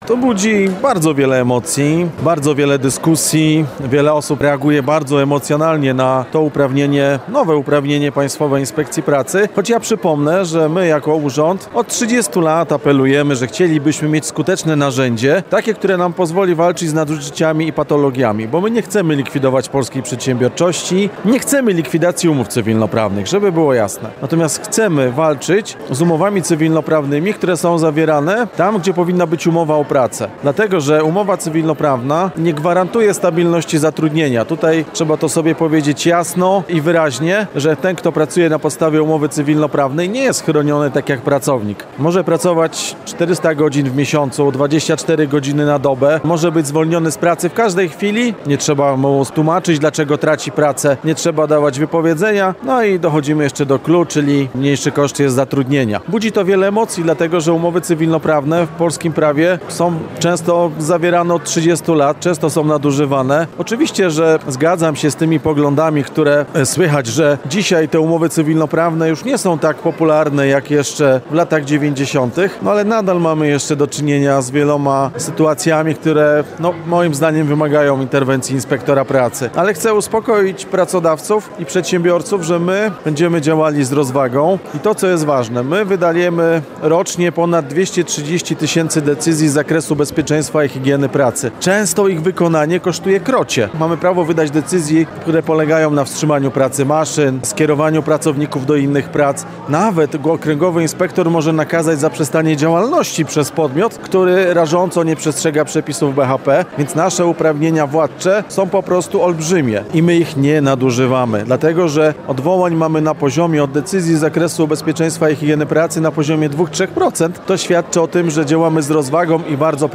Rozmowa z Głównym Inspektorem Pracy Marcinem Staneckim